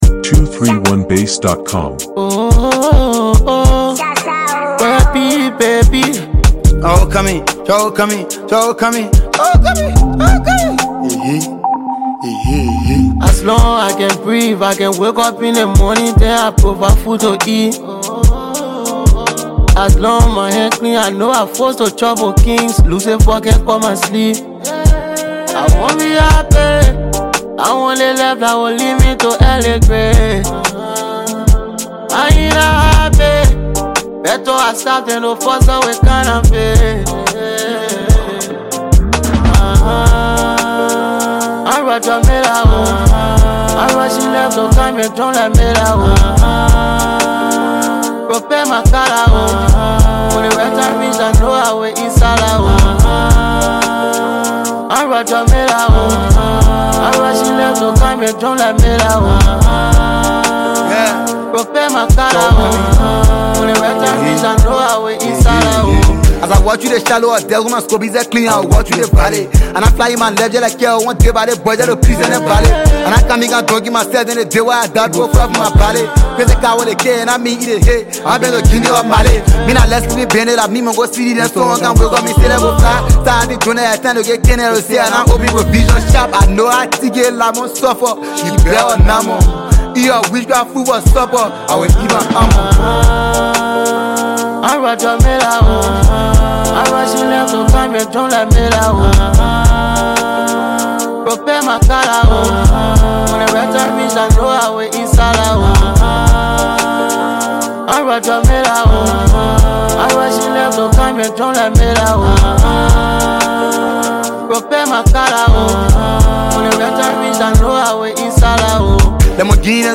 emotive Afro drill track